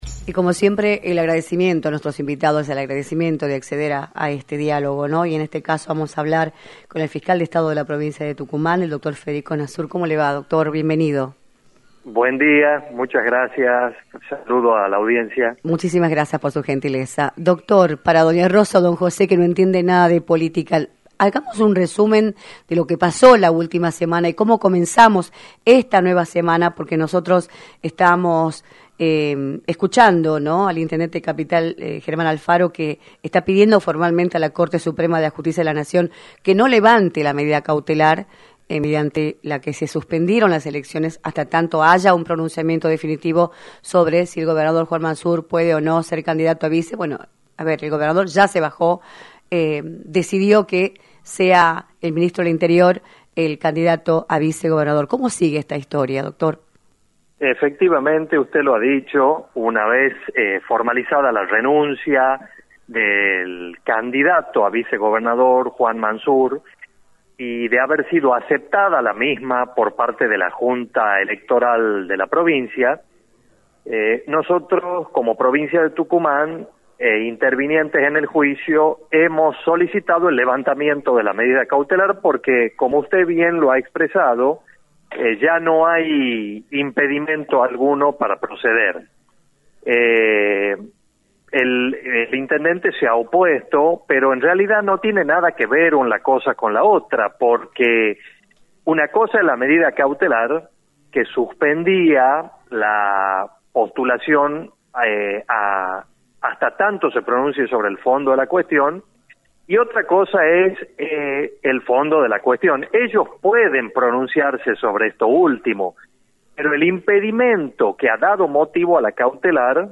Federico Nazur, Fiscal de Estado de la provincia, informó en “Libertad de Expresión” por la 106.9, como se encuentra el escenario político, electoral y legal de la provincia, luego de la suspensión de los comicios que estaban previstos para el 14 de mayo, lo cual derivó en la declinación de Juan Manzur a su candidatura como Vicegobernador y la designación de Miguel Acevedo, Ministro del Interior, como compañero de fórmula de Osvaldo Jaldo.